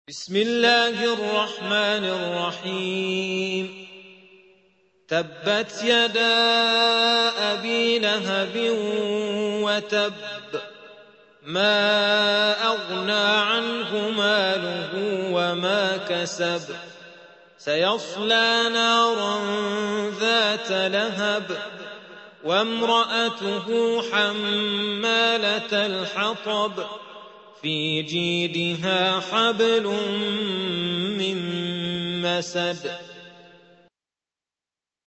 111. سورة المسد / القارئ